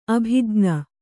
♪ abhijŋa